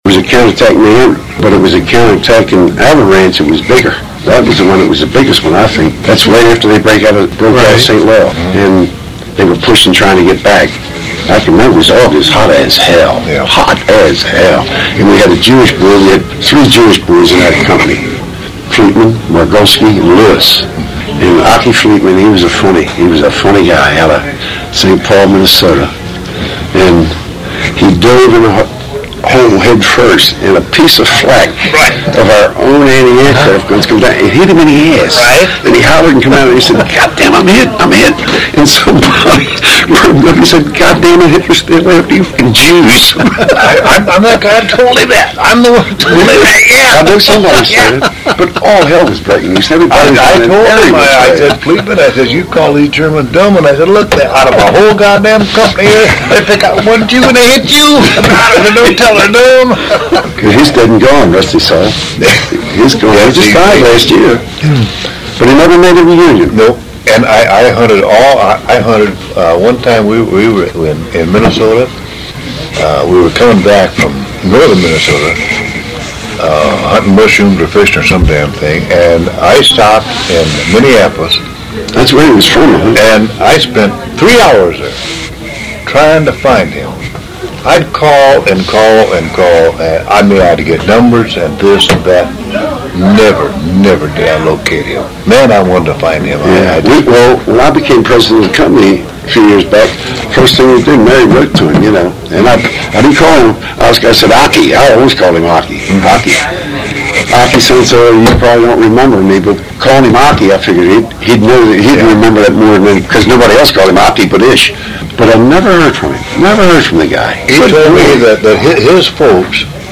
When I began recording the stories of veterans of the 712th Tank Battalion, with which my father served, I would take my little Sony Walkman style recorder into the hospitality room.
Other times the audio, with some background noise, would be sufficiently clear to put on a CD or post on the Internet.
I've broken the conversation into four clips.